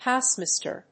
アクセント・音節hóuse・màster